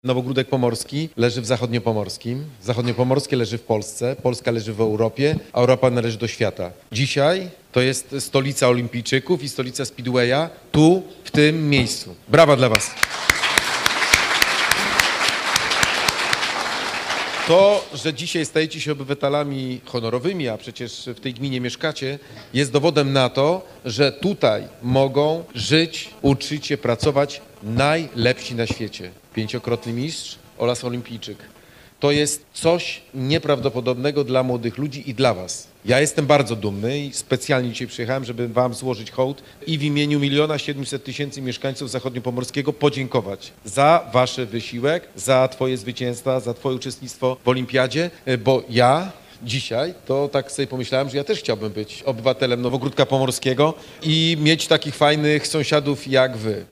Podczas uroczystości utalentowanym sportowcom z naszego województwa podziękował wojewoda Adam Rudawski.